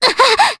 Scarlet-Vox_Damage_Jp_02.wav